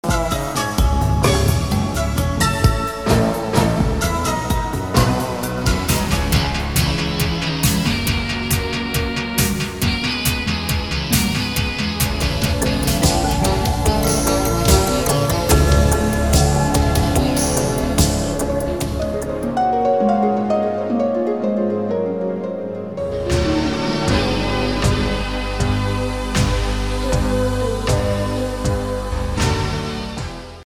Like a stack of synthesizers and stuff, really: